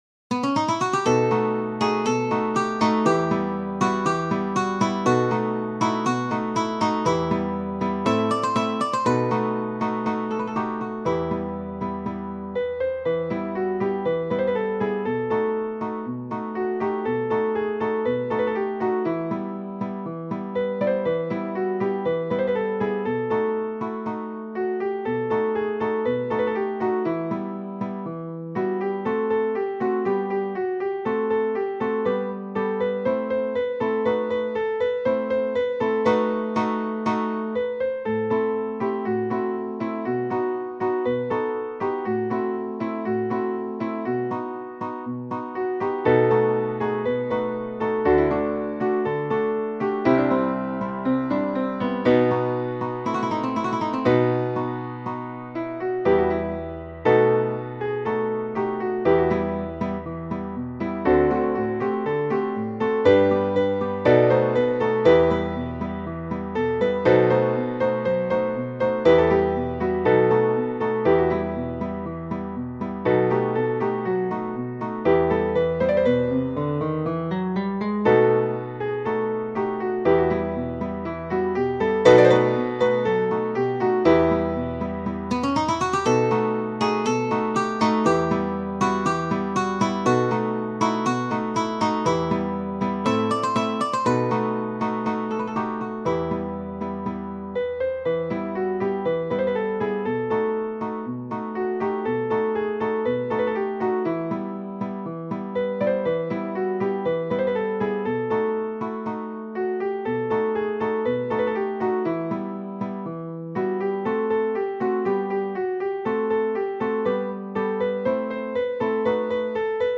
Genere: Ballabili
paso doble